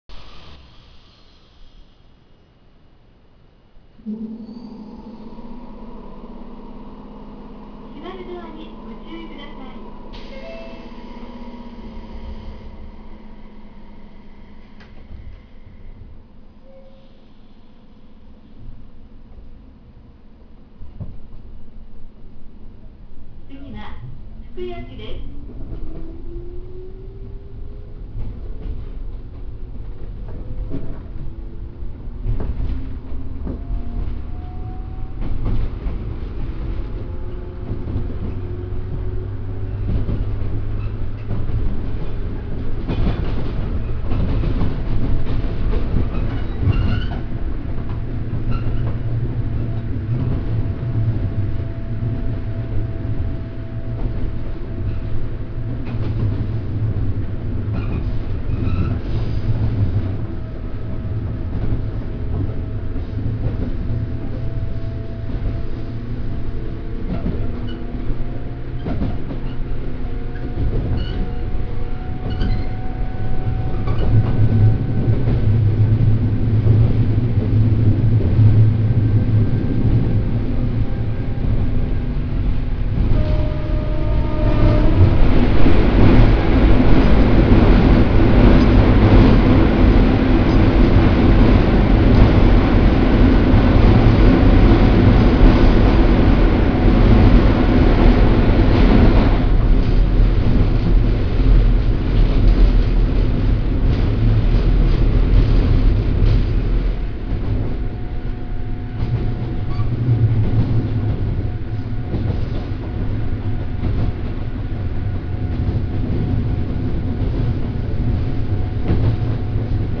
・103系1500番台（高音モーター）走行音
1500番台は、音の面ではブレーキ解除音とドア開閉音が201系のものに変わっていますが、走行音自体は103系のまま、変わっていません。
↑での収録も、福吉で賑やかな人が乗ってくるまで６区間連続で収録できてしまいました。